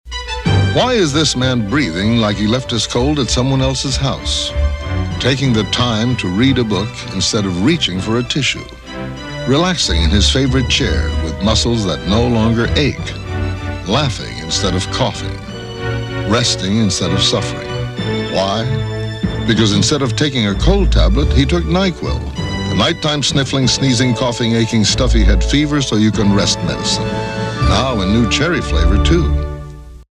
RADIO ADVERTISEMENT: VICKS’ NYQUIL COUGH SYRUP
Radio-Commercials-1980s-Nyquil-Audio-Enhanced.mp3